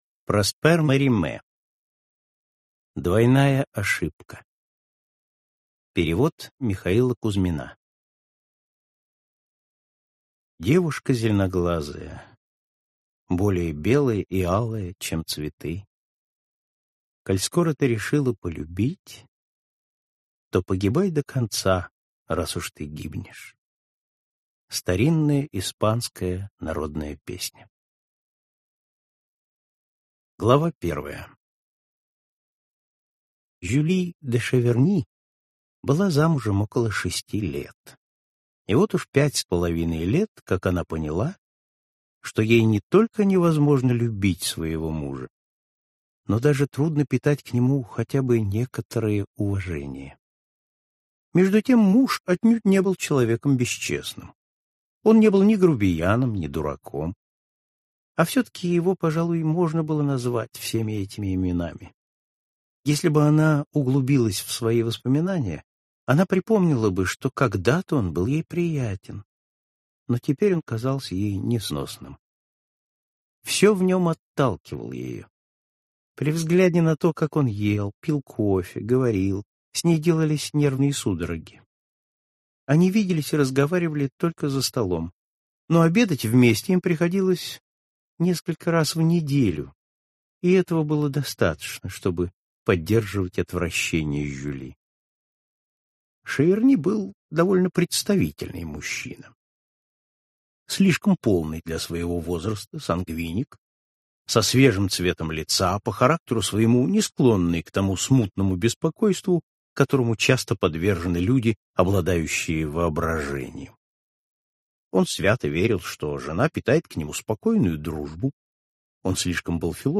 Аудиокнига Классика зарубежного рассказа № 8 | Библиотека аудиокниг